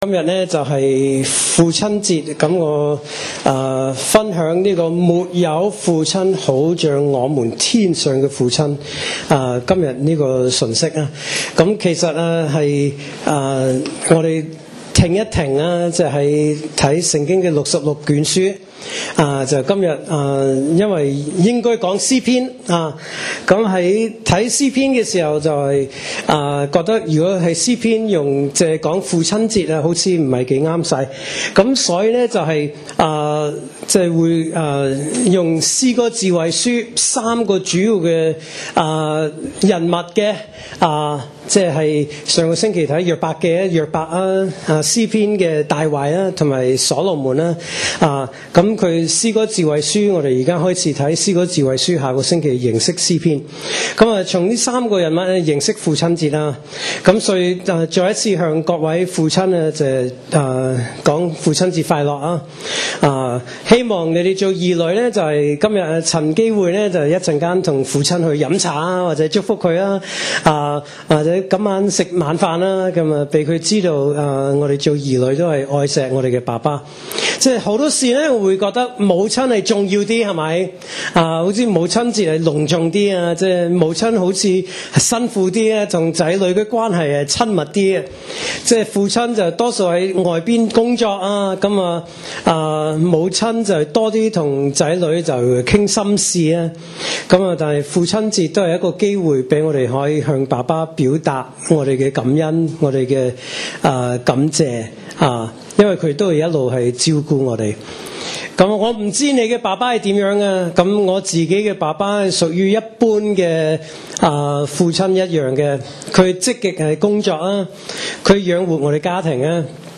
來自講道系列 "節日性講道"